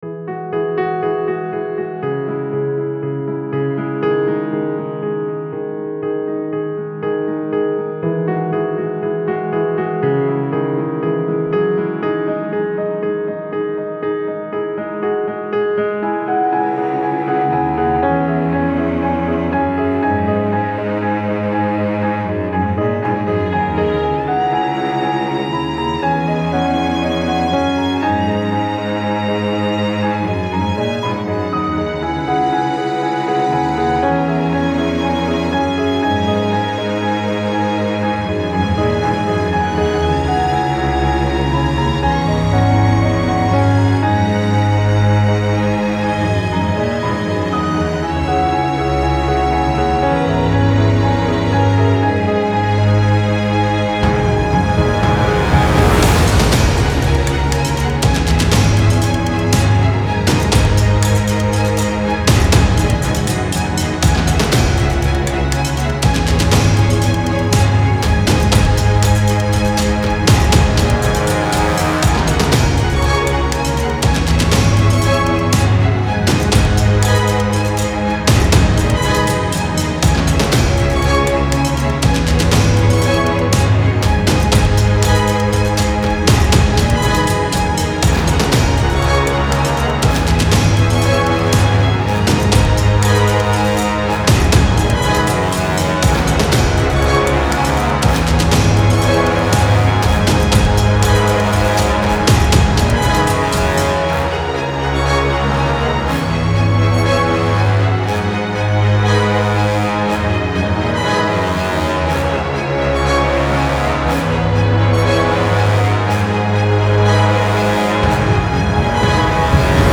Style Style OrchestralSoundtrack
Mood Mood EpicUplifting
Featured Featured BrassCelloChoirDrumsPianoStringsVocals
BPM BPM 120